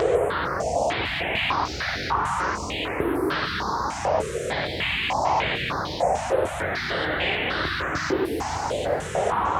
STK_MovingNoiseD-100_01.wav